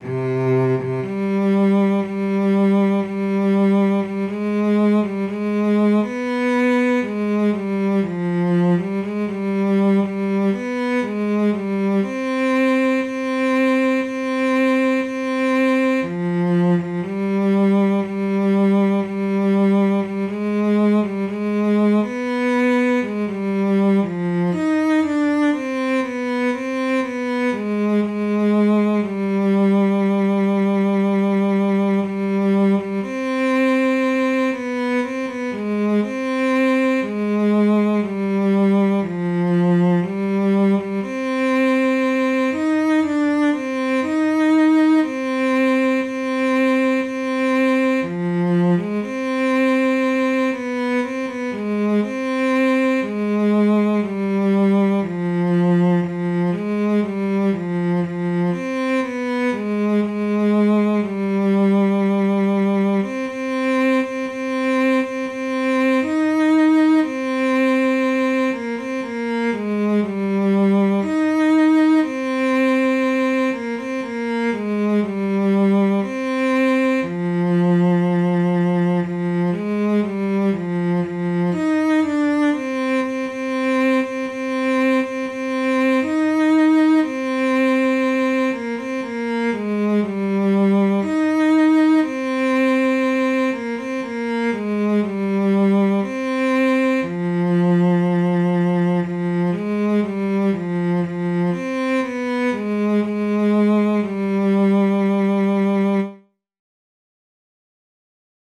Jewish Folk Song - Chabad-Lubavitch Melody
C minor ♩= 60 bpm